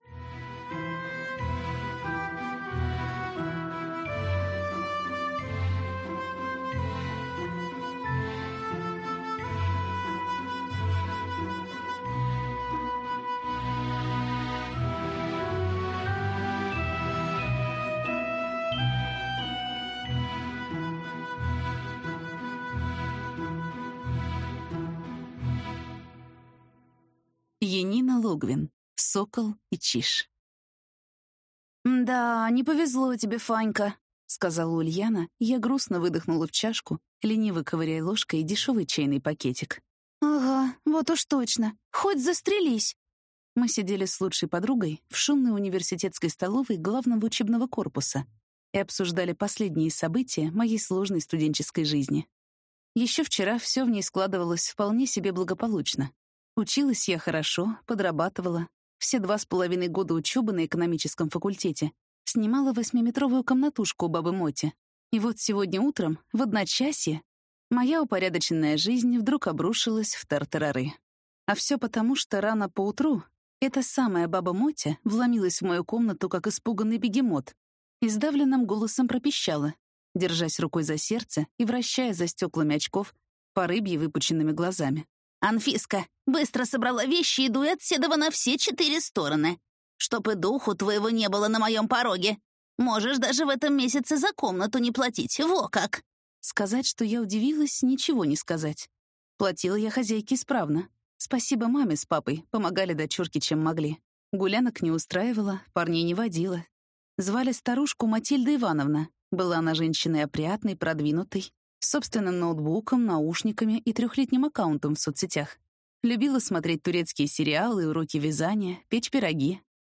Аудиокнига Сокол и Чиж | Библиотека аудиокниг